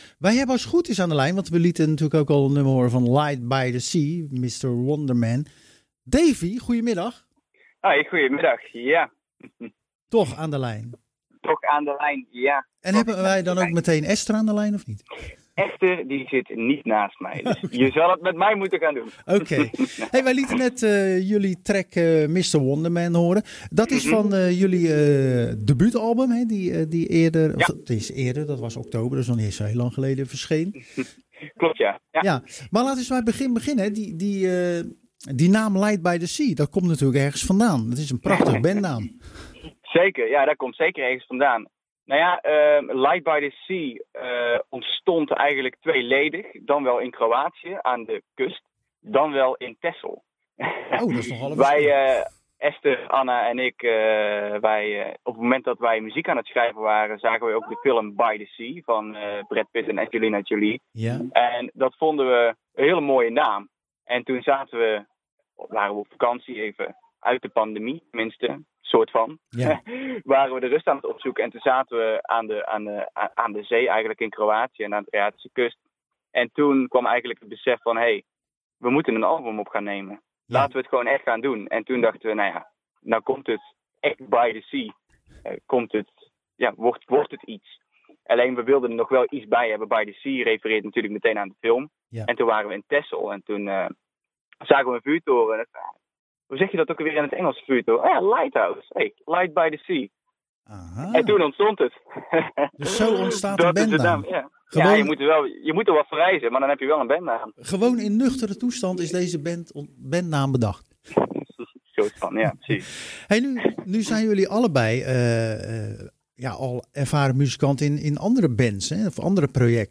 Tijdens de wekelijkse editie van Zwaardvis spraken we het muzikale duo Light By The Sea over hun via een crowdfundingsactie tot stand gekomen debuutalbum 'Only Death Makes Icons'.Het is een ode aan de muzikale helden die het duo bewonderd waardoor het een zeer uiteenlopend album is geworden.� �